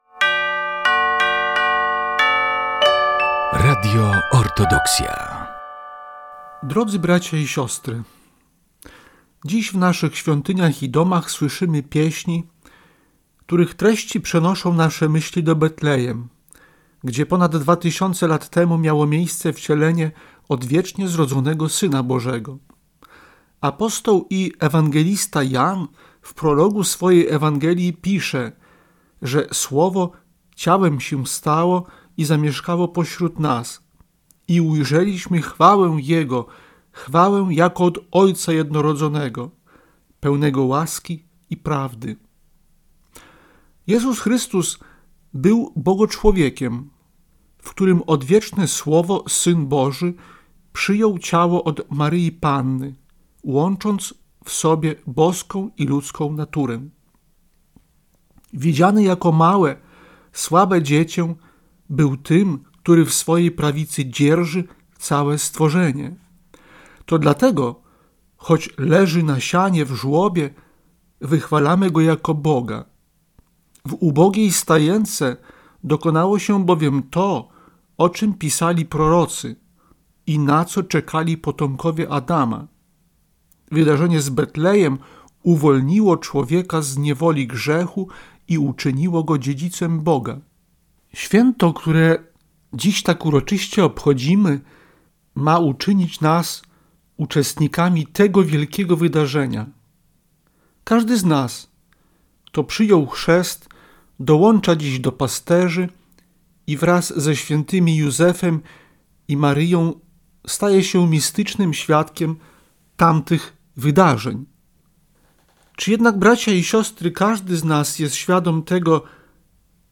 Orędzie Bożonarodzeniowe Arcybiskupa Jakuba 2025
Zapraszamy do wysłuchania bożonarodzeniowego orędzia Jego Ekscelencji Najprzewielebniejszego Jakuba Arcybiskupa Białostockiego i Gdańskiego.